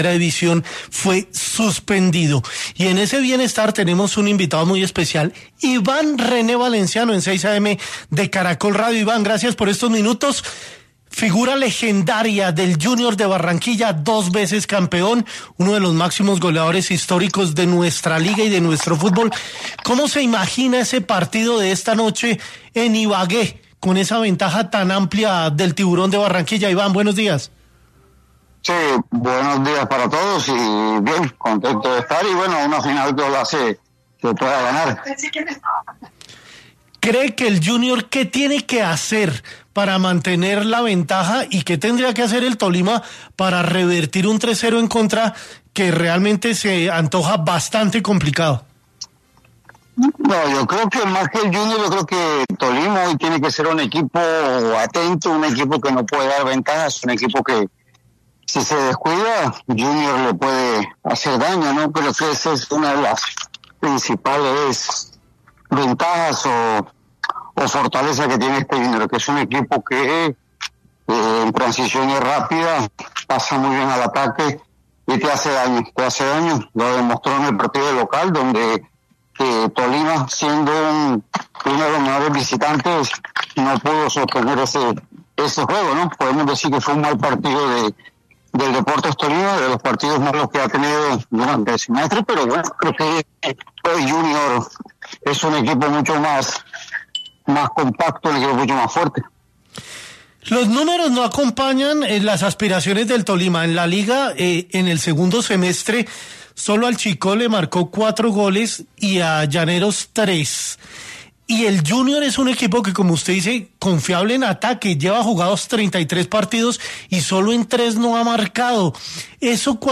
En diálogo con 6 AM de Caracol Radio, el Bombardero se sumó al debate de los últimos días sobre si creía que gran parte del país estaba en contra de que Junior sea el vencedor en la final ante Tolima.